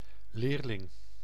Ääntäminen
IPA: /ˈleːr.lɪŋ/ IPA: [lɪːr.lɪŋ]